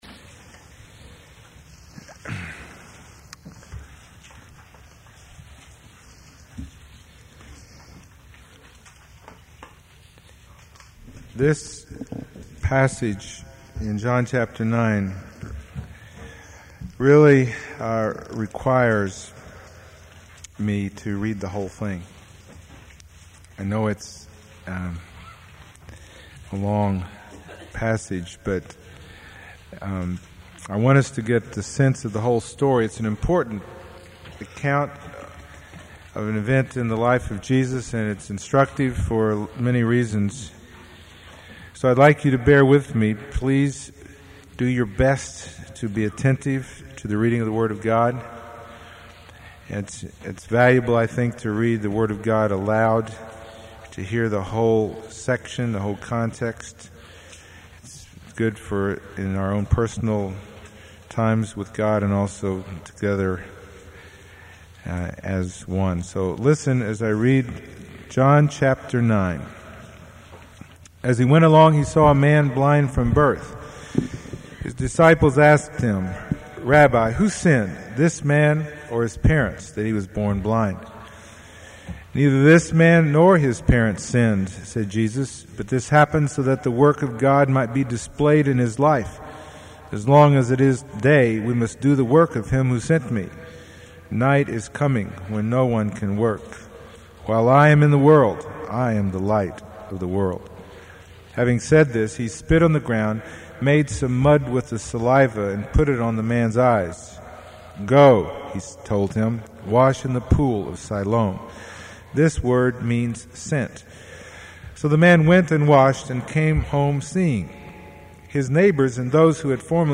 No Series Passage: John 9:1-41 Service Type: Sunday Morning %todo_render% « Nicodemus is Every Man Triumphal Entry